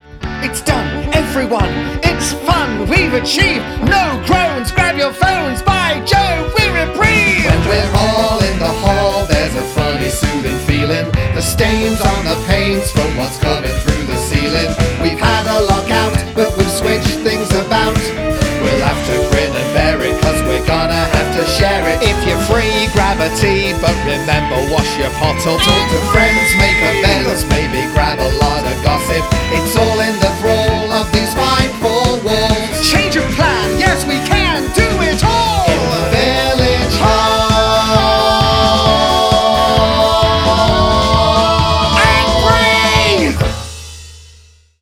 These are performed in similar styles to the finished versions but have different orchestrations and keys.